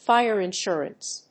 fíre insùrance